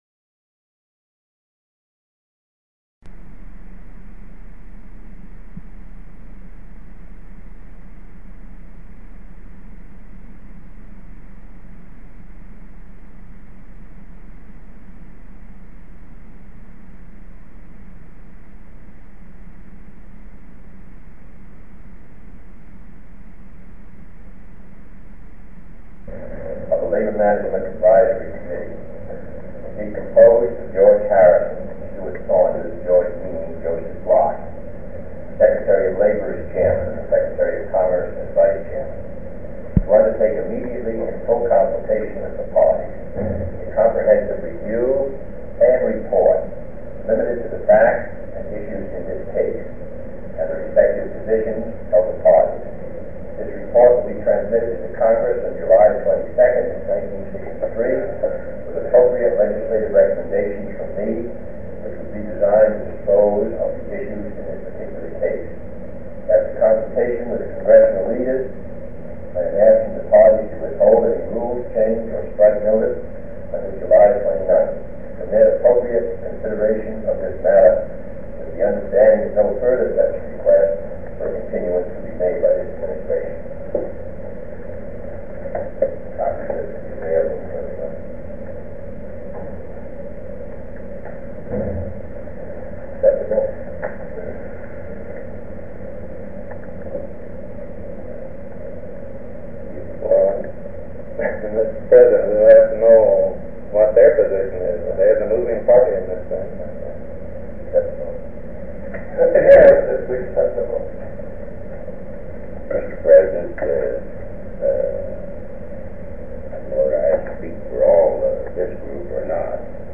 Meetings: Tape 97/A33. Railroad Work Rules Dispute Meeting with Union and Management Leaders, 10 July 1963
Secret White House Tapes